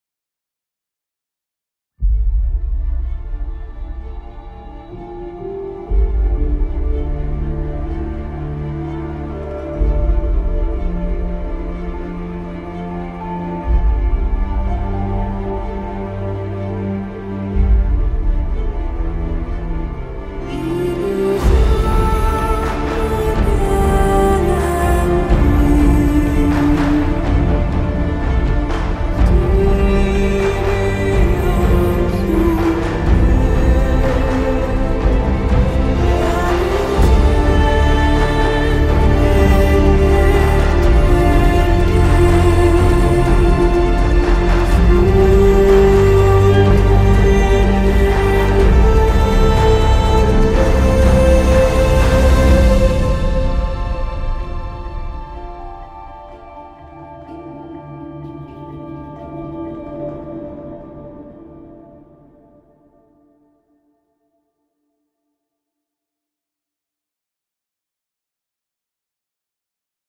Oríginal Televison Soundtrack